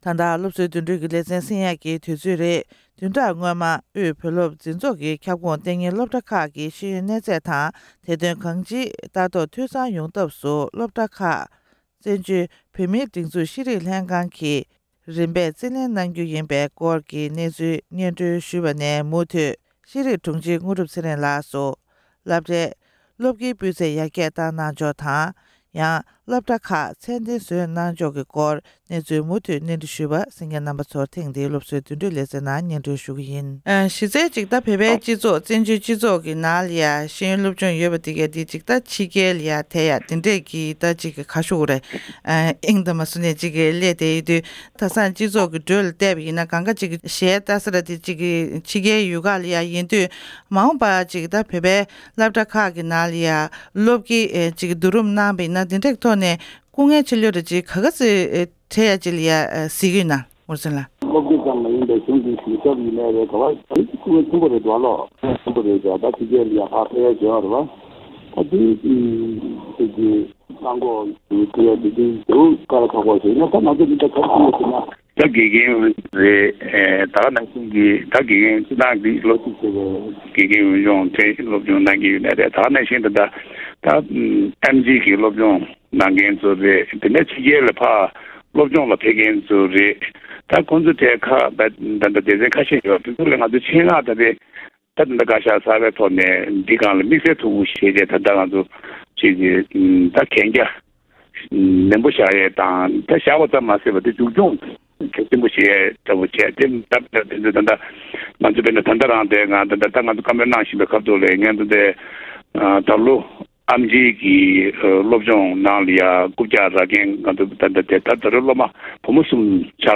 ཤེས་རིག་ལས་ཁུངས་ནས་རྒྱ་གར་དབུས་བོད་སློབ་འཛིན་ཚོགས་ཁྱབ་ཁོངས་སློབ་གྲྭ་ཁག་རྩིས་ལེན་གནང་རྗེས། སློབ་དགེའི་སྤུད་ཚད་ཡར་རྒྱས་གཏོང་ཕྱོགས་དང་། སློབ་གྲྭ་ཁག་ཚད་ལྡན་བཟོ་ཕྱོགས་སྐོར་གླེང་མོལ་ཞུས་པའི་དམིགས་བསལ་ལས་རིམ།